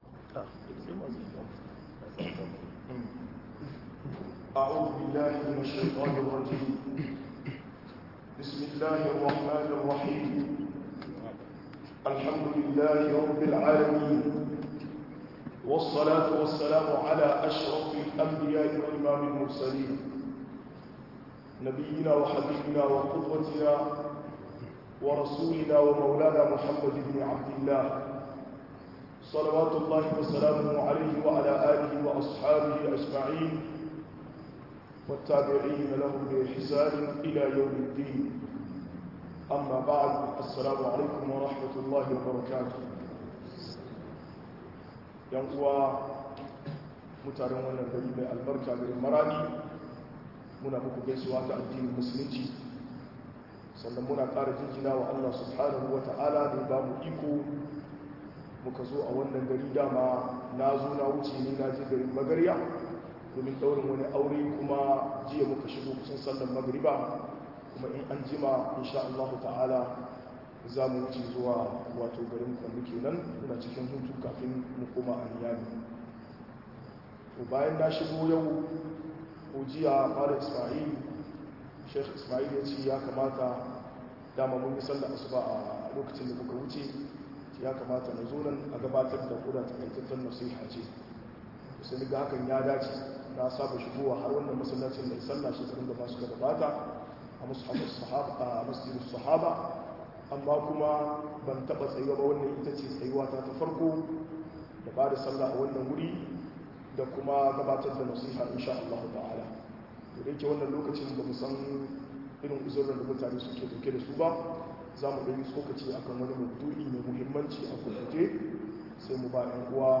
Kurakurai cikin tarbiyya-2 - MUHADARA